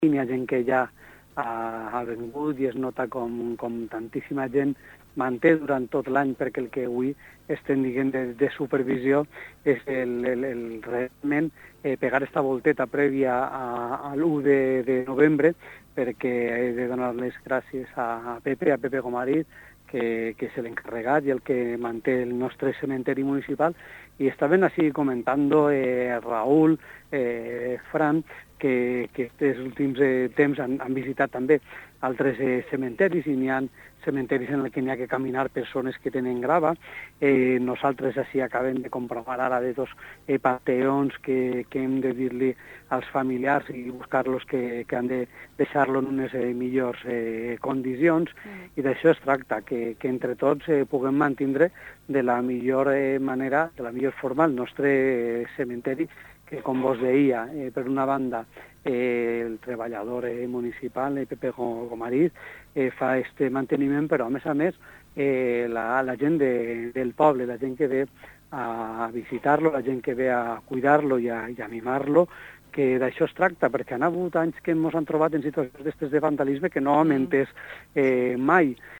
ENTREVISTA CON EL ALCALDE Y EL EDIL